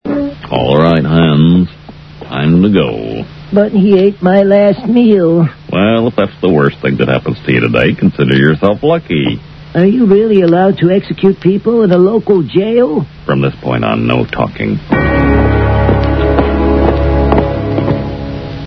Voiced by Dan Castellaneta